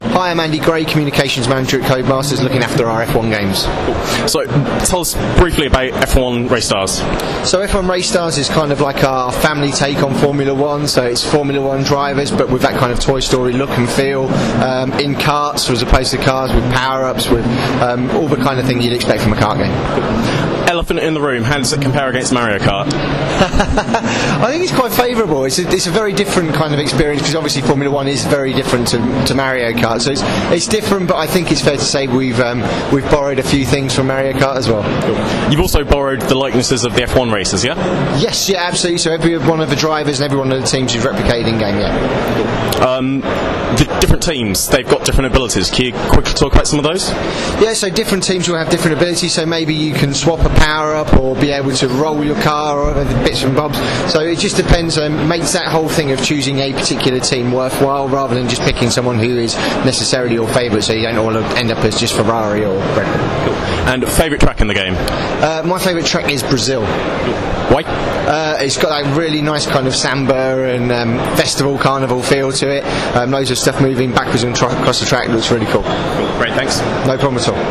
Micro Interview